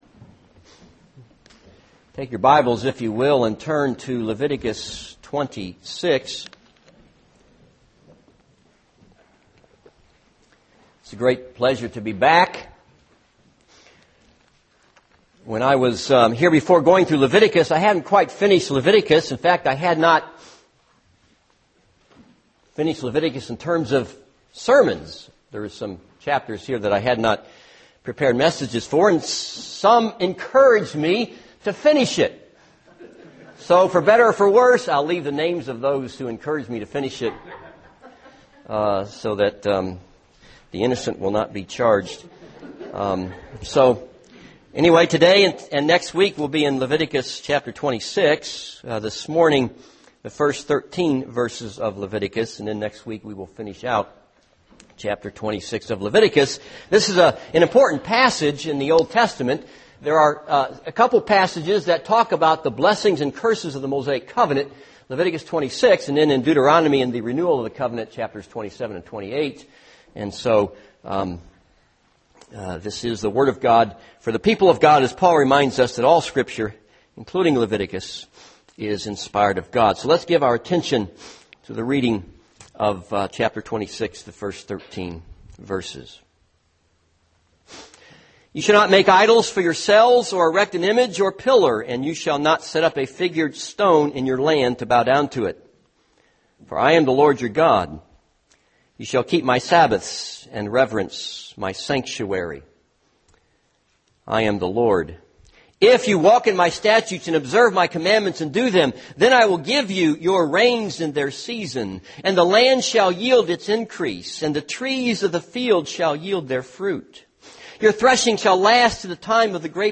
This is a sermon on Leviticus 26:1-13.